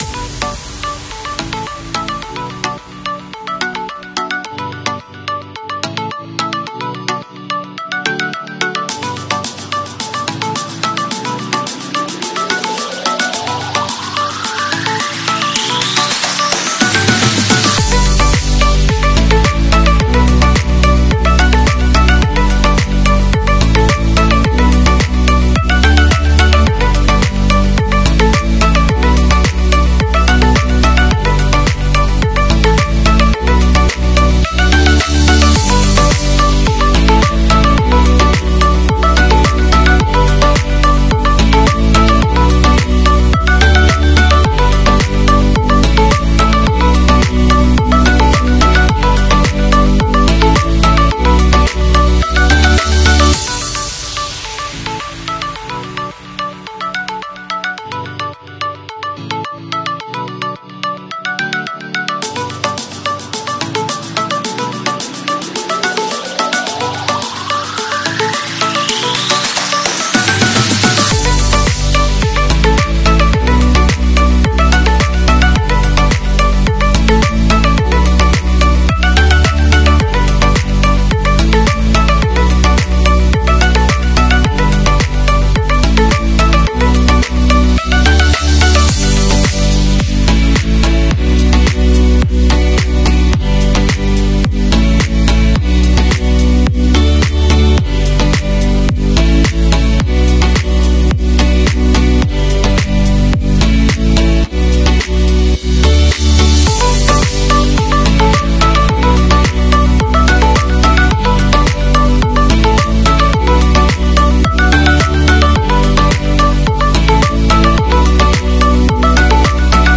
5V to 12V DC motor sound effects free download
5V to 12V DC motor speed controller irfz44